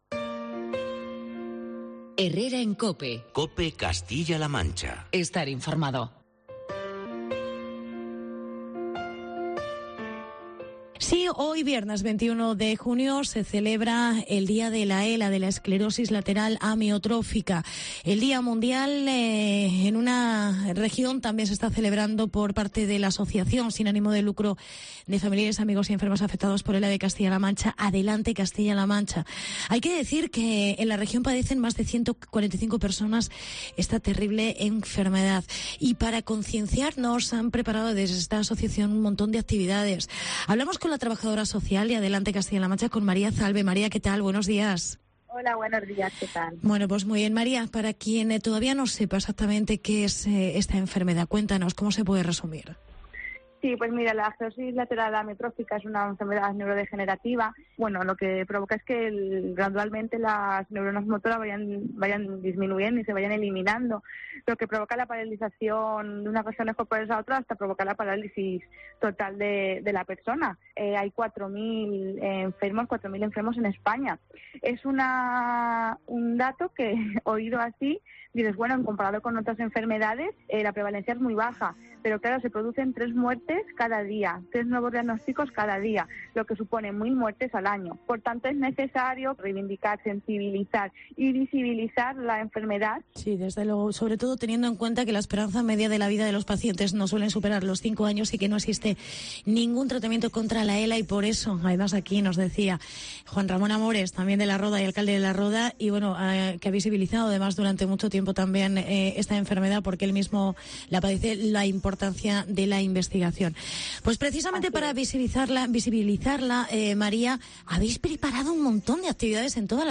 Más de 145 afectados de E.L.A en CLM. Entrevista